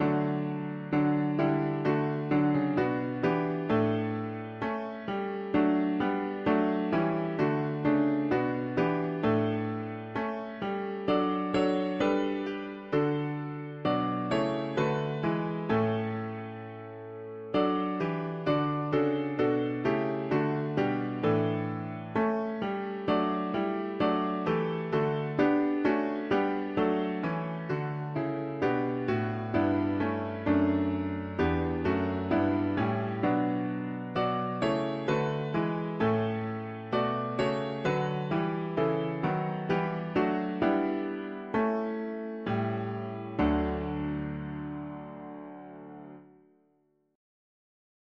Bright burning sun with golden beam, soft shining mo… english theist 4part chords
Key: D major Meter: LM